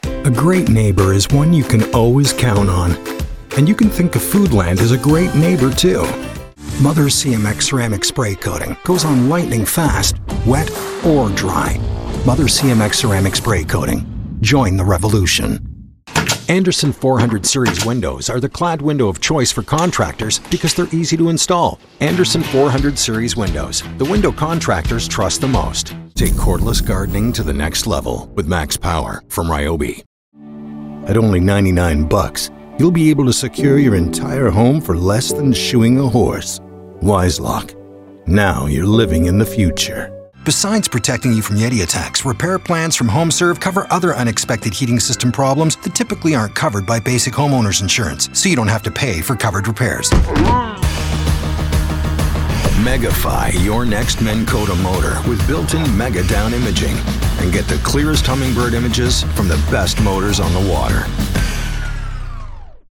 Englisch (Kanadisch)
Ich bin ein freundlicher und zuverlässiger professioneller nordamerikanischer englischer Synchronsprecher und lebe in Kanada.
Freundlich
Konversation